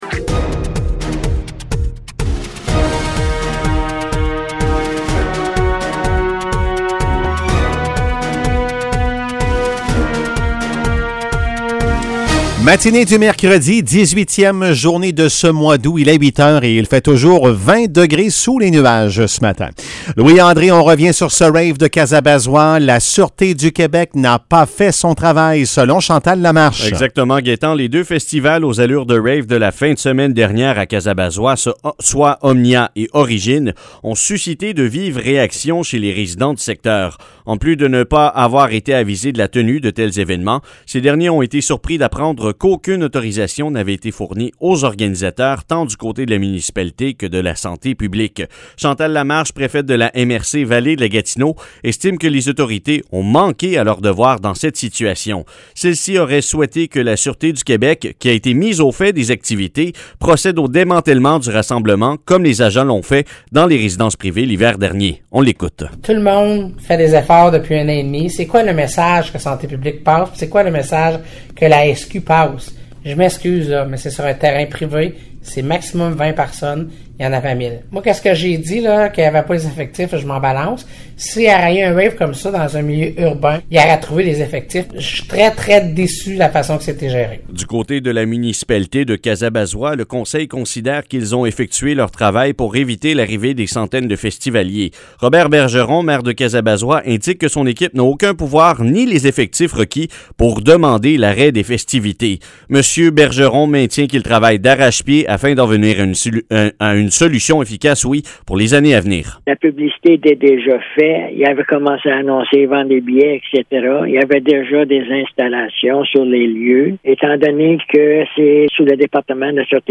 Nouvelles locales - 18 août 2021 - 8 h